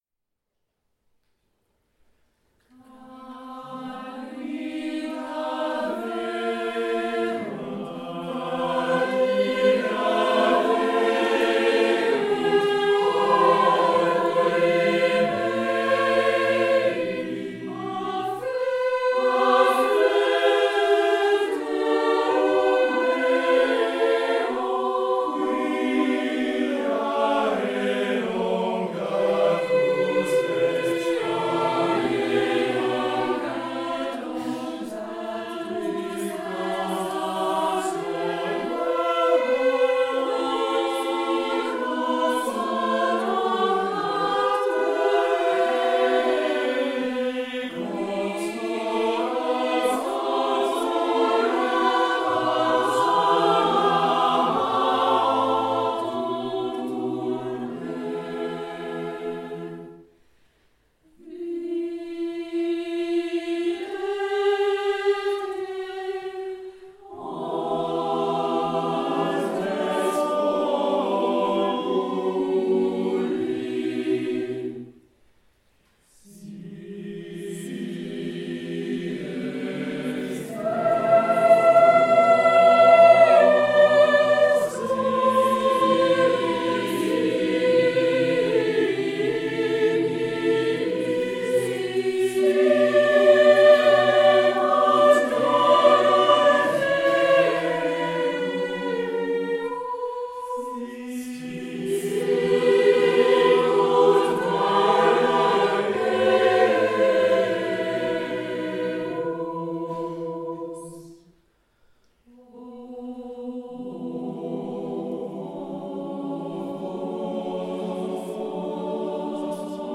Concert Temple Protestant Montigny le Bretonneux 8 avril 2016